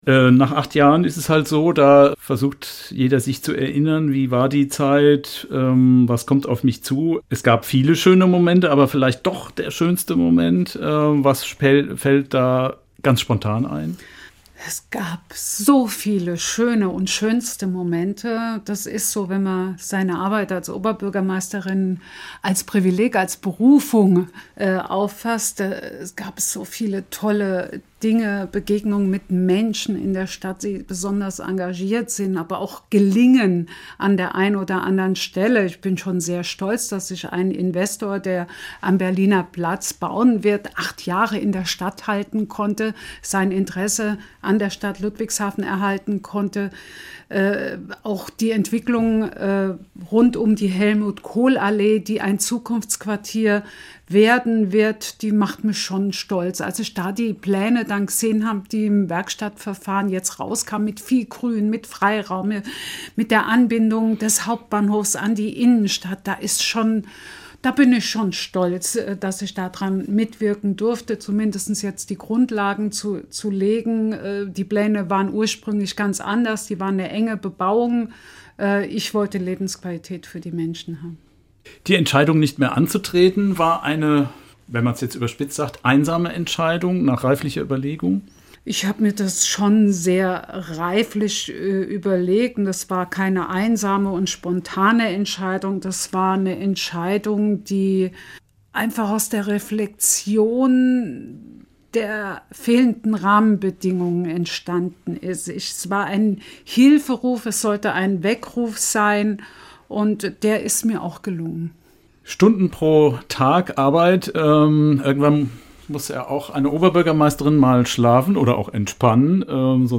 Welche schönen, aber auch welche schlimmen Momente sie erleben musste und welche Probleme die Stadt lösen muss, erzählt sie im SWR-Interview.